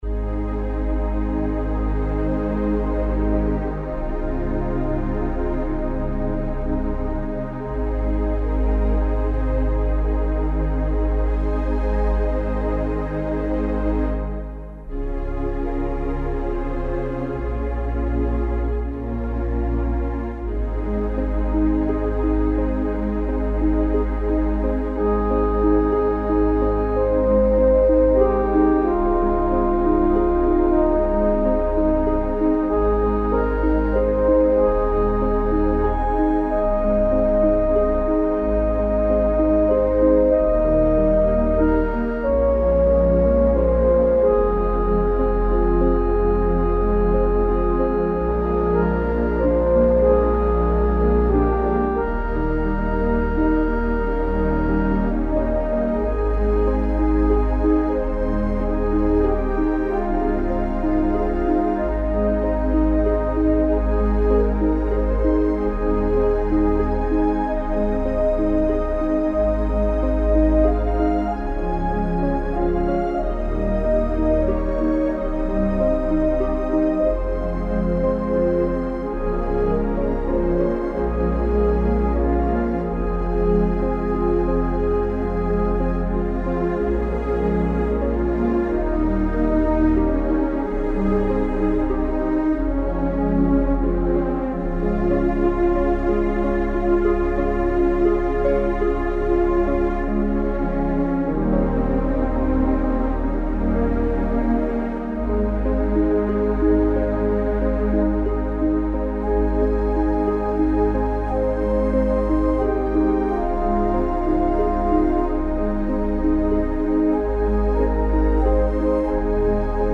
For the accompaniment and the solos we choose a string sound and also for the solos the horn, flute and english horn.
The solution is to pre-record the harp accompaniment using the instrument’s MIDI Record facility and then play this back as part of the performance.